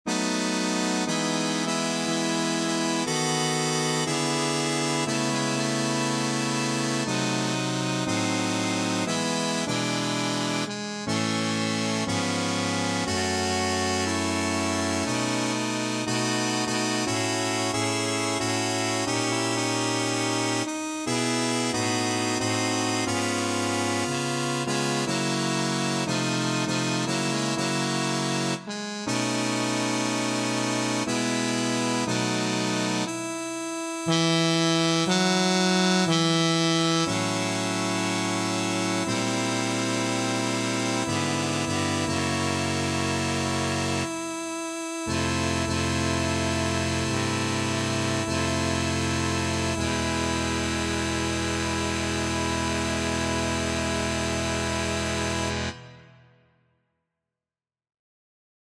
Key written in: E Major
How many parts: 4
Type: Barbershop
All Parts mix: